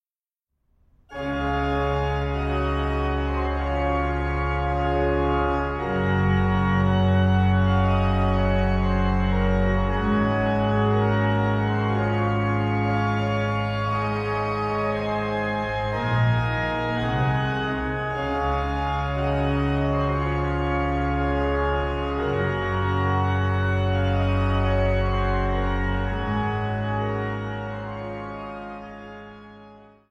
Führer-Skrabl-Orgel in der Evangelischen Kirche Saarlouis